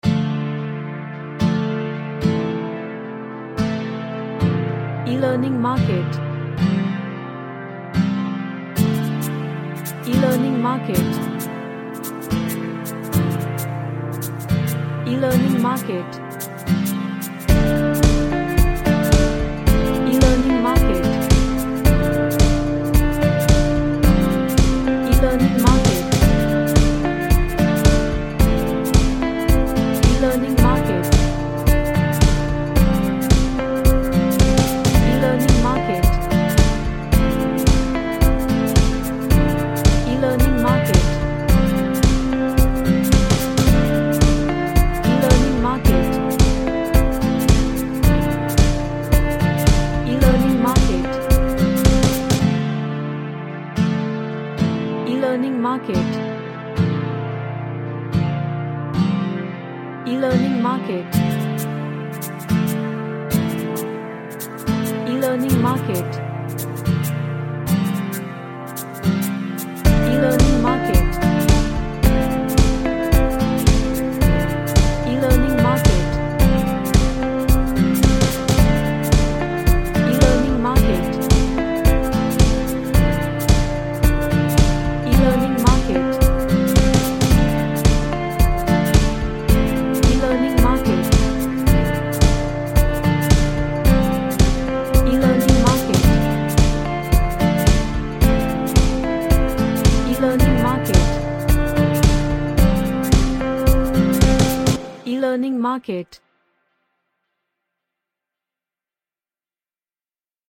An Acoustic track with reverbed strings.
Emotional